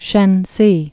(shĕnsē)